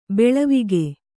♪ beḷavige